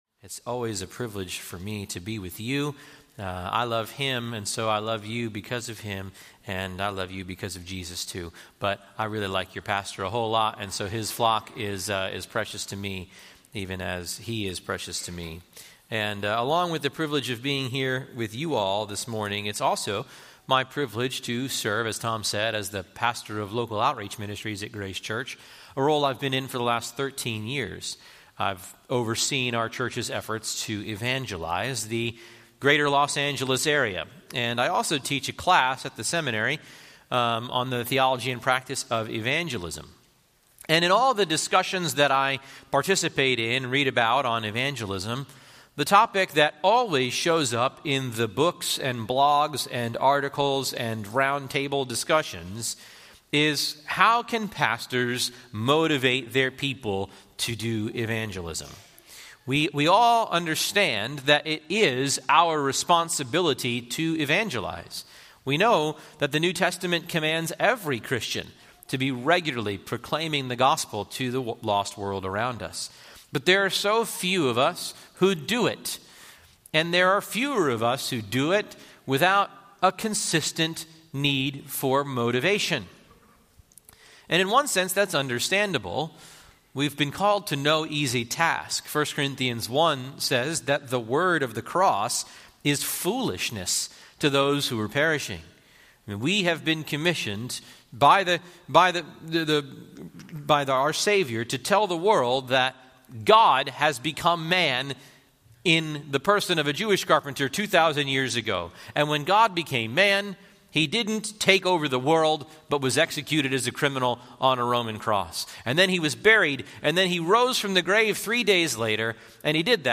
Sermons Countryside Conference 2025 - The Essentials